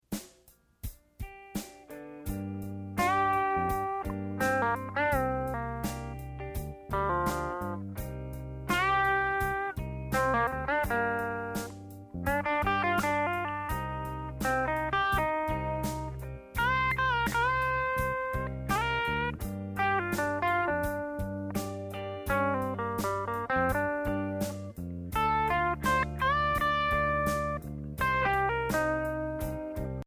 Voicing: Guitar Method